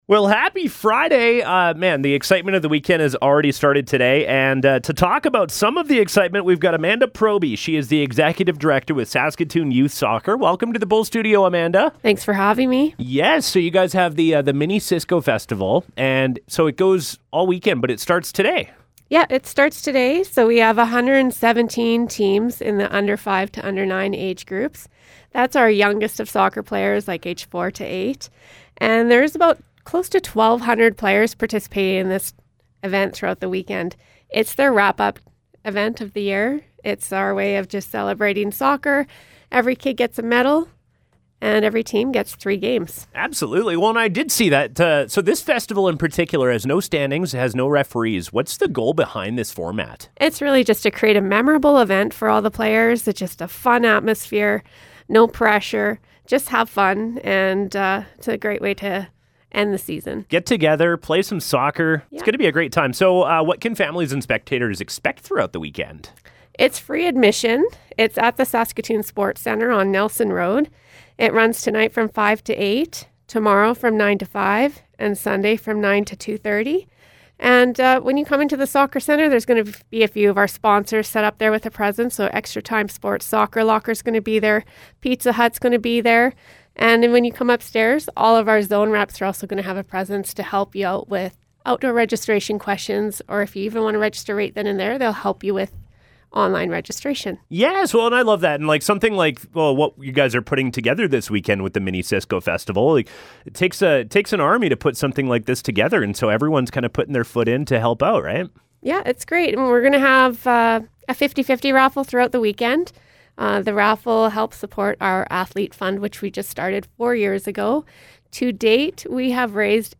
Interview: Saskatoon Youth Soccer